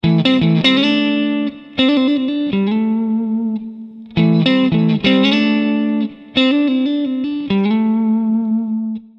Fraseggio blues 08
L'utilizzo delle doppie note ha un'ottima resa sonora.